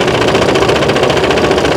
tank_tracks.wav